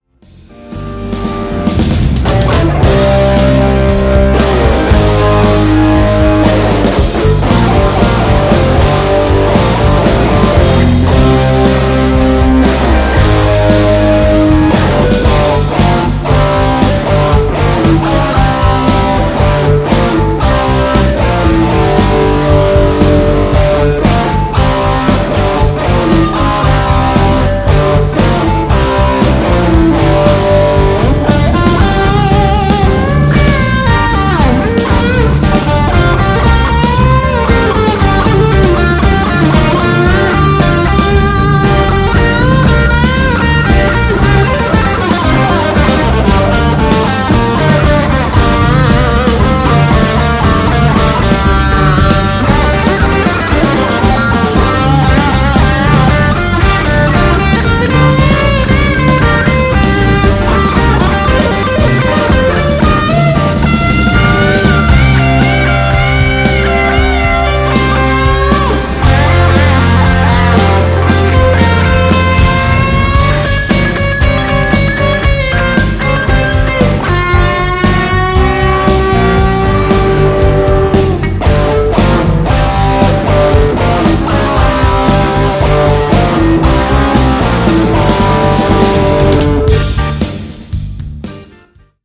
Bullet Strat вроде... за полторы тысячи гривен.